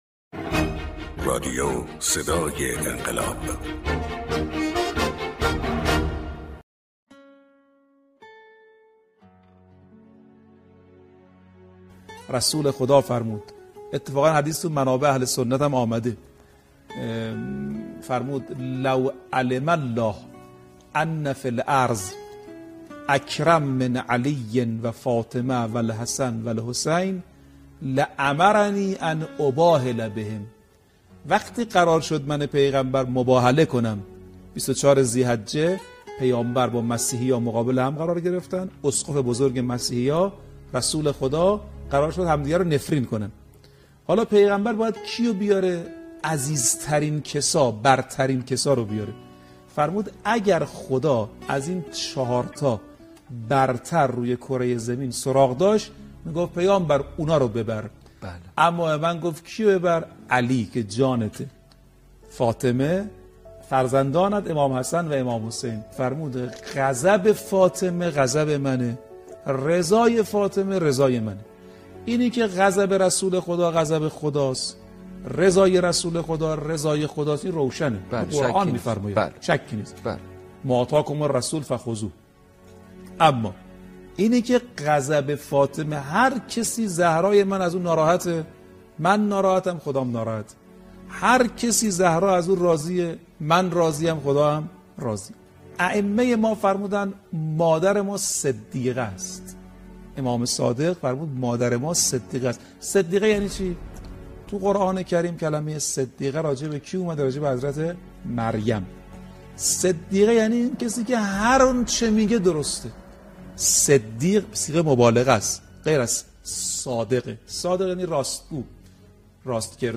سخنران